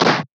Cartoon Punch Cassette C
2D Animation Cassette Comic Fighting Game Punch SFX sound effect free sound royalty free Movies & TV